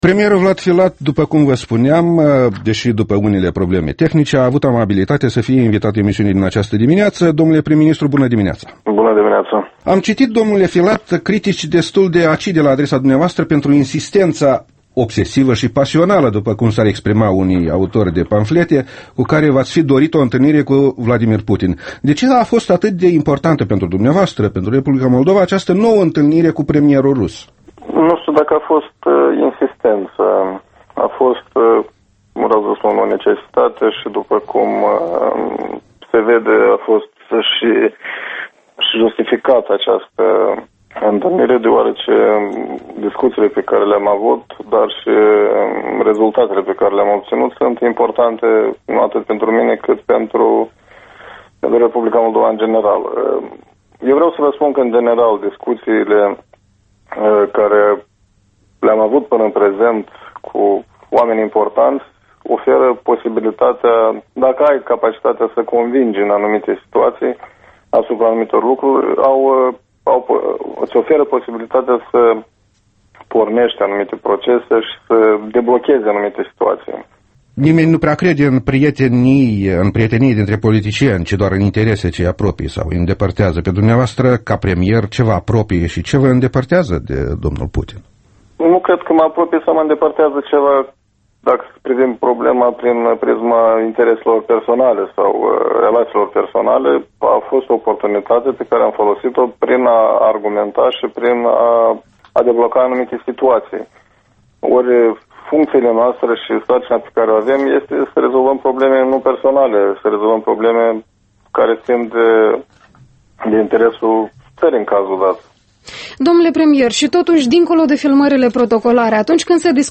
Interviul matinal EL: cu premierul Vlad Filat